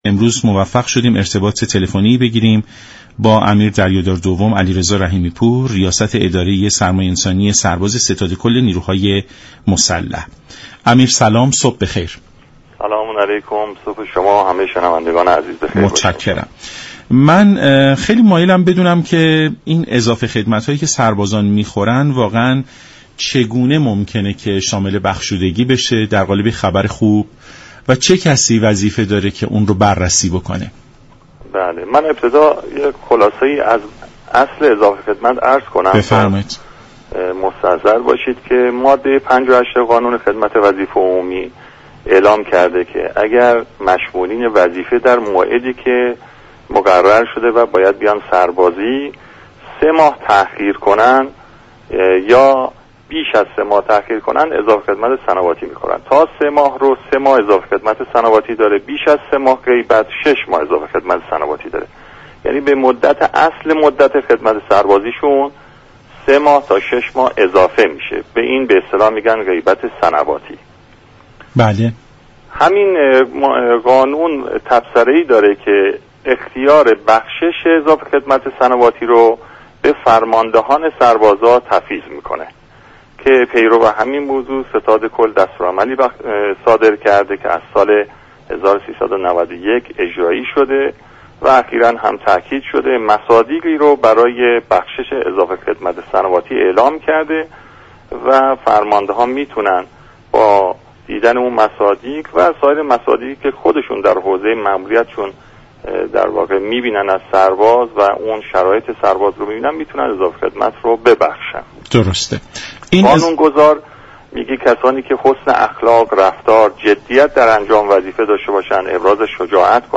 به گزارش شبكه رادیوی ایران، امیر دریادار دوم علیرضا رحیمی پور رئیس اداره سرمایه انسانی سرباز ستاد كل نیروهای مسلح در برنامه «سلام صبح بخیر» رادیو ایران درباره اضافه خدمت سربازی صحبت كرد و در پاسخ این پرسش كه این اضافه چگونه بخشیده خواهد شد؟